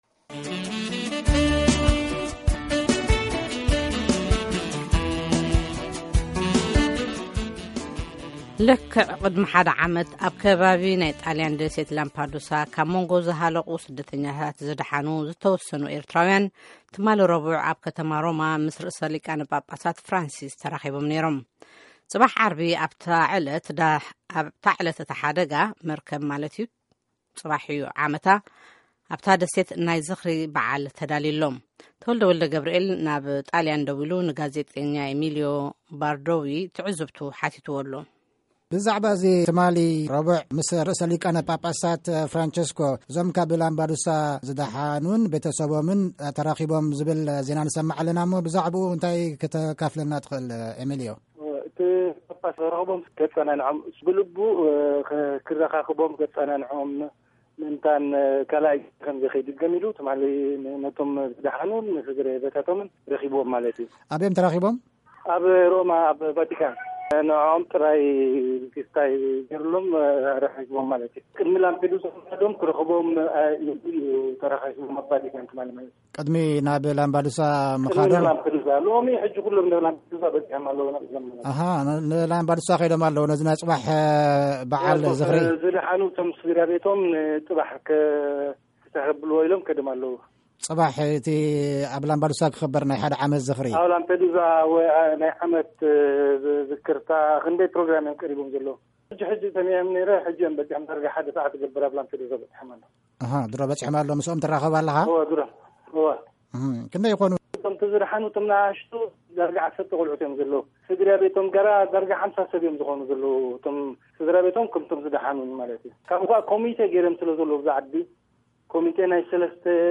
ቃለ-መጠይቅ